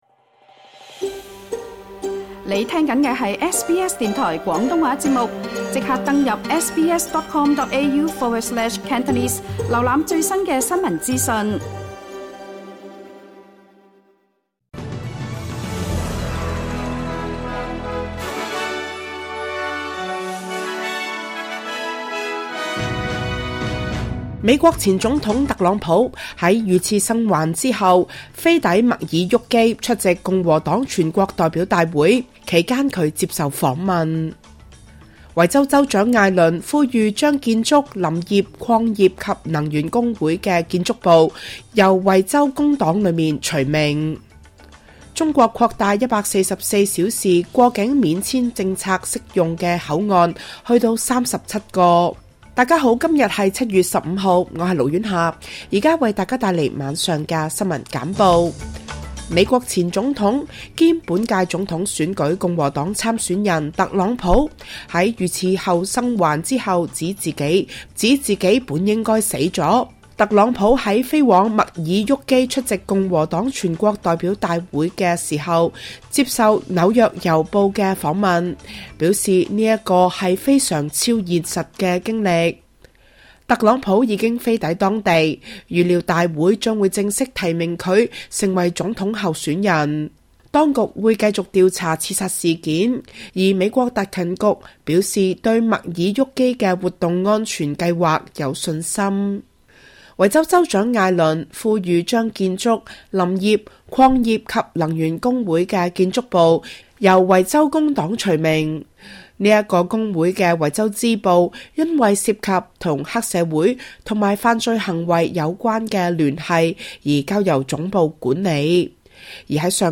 SBS 晚間新聞（2024年7月15日）